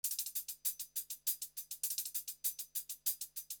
HIHAT LOP6.wav